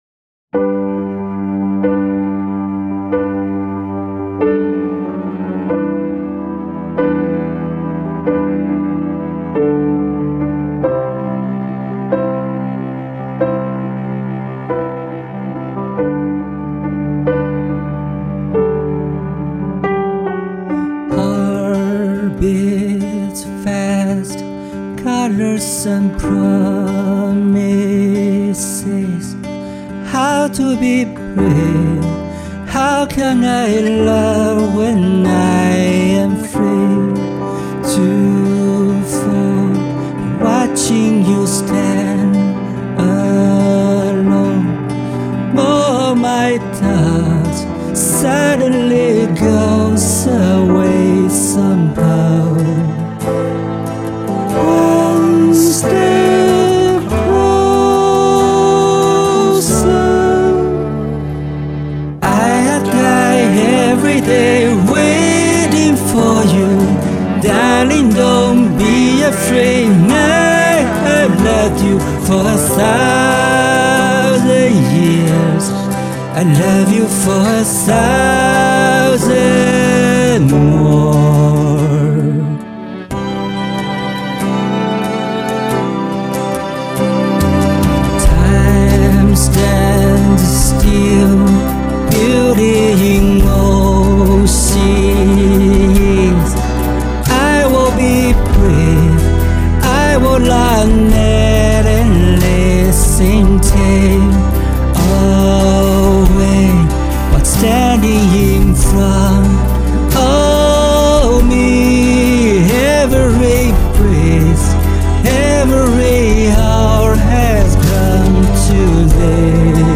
耳机效果应该会好些